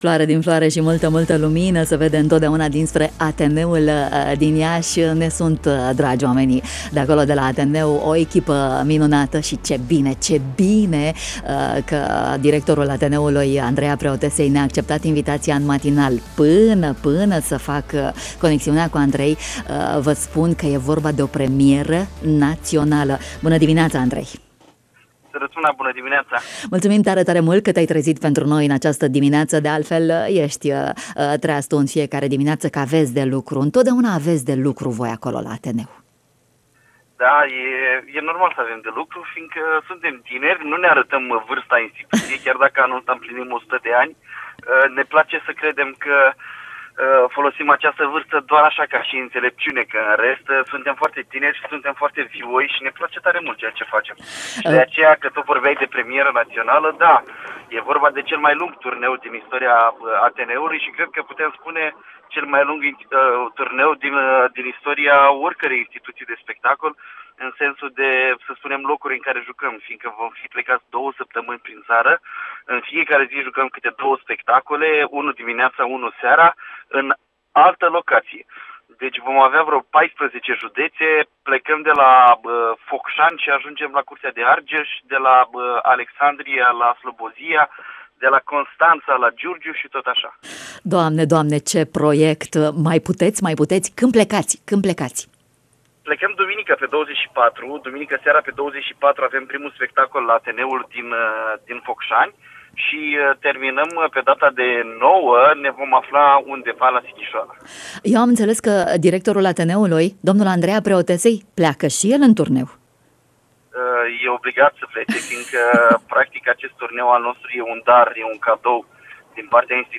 ne-a vorbit în emisunea Bună Dimineaţa